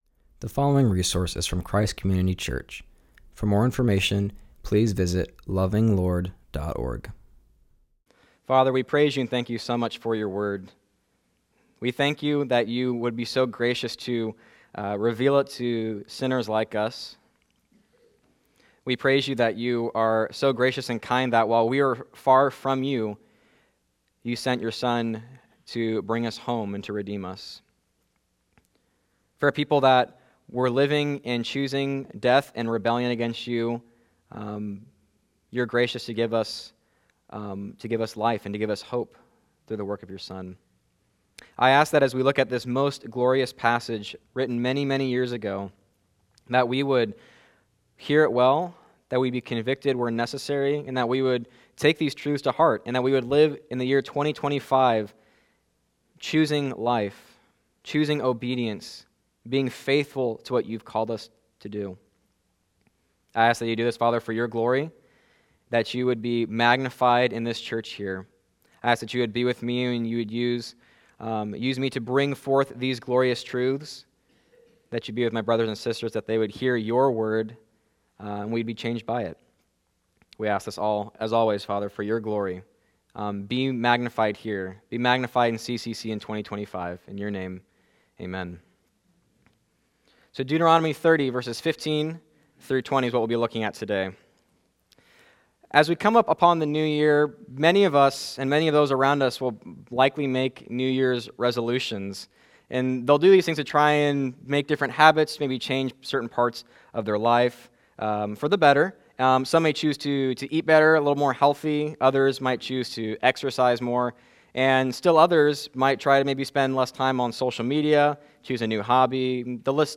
preaches on Deuteronomy 30:15-20.